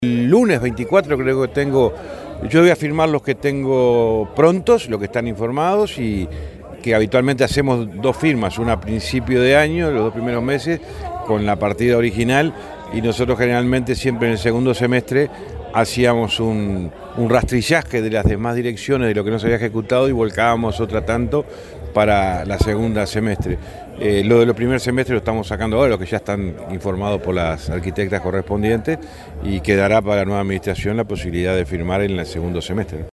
Escuchamos al ministro Falero sobre la visita de este lunes a Colonia…